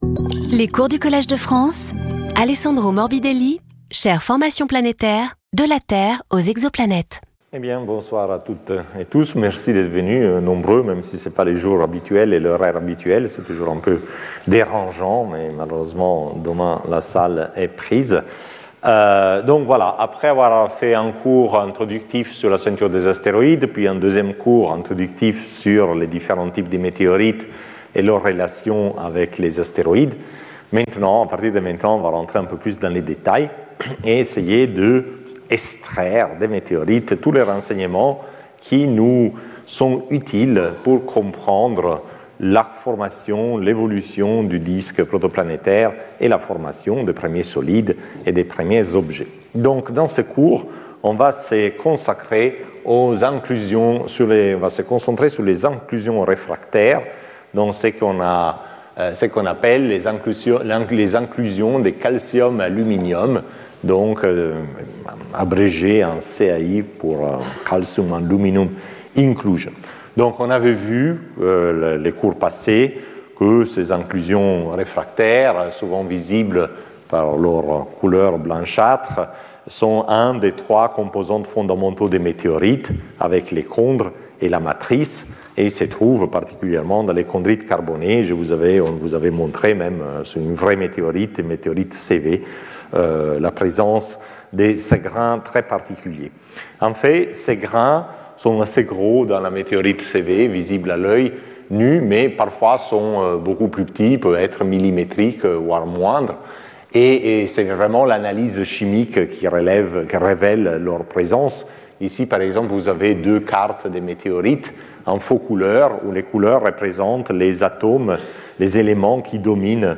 Alessandro Morbidelli Professeur du Collège de France
Cours